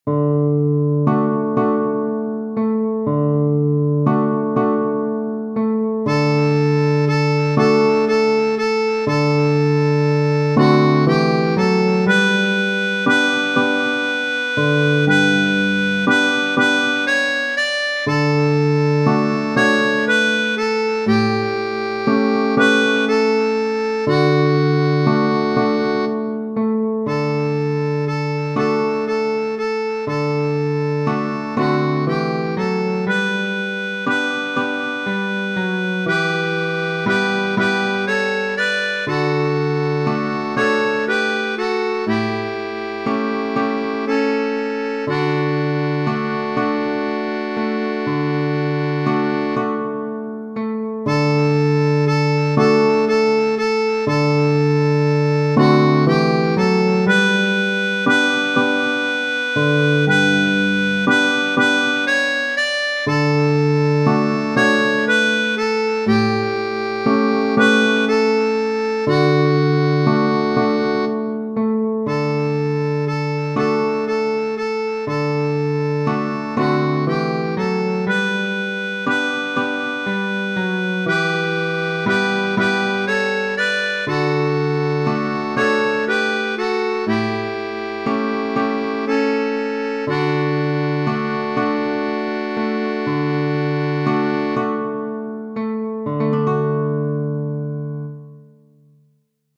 Tradizionale Genere: Sociali e Patriottiche Tutti mi dicon Maremma, Maremma ma a me mi pare una Maremma amara.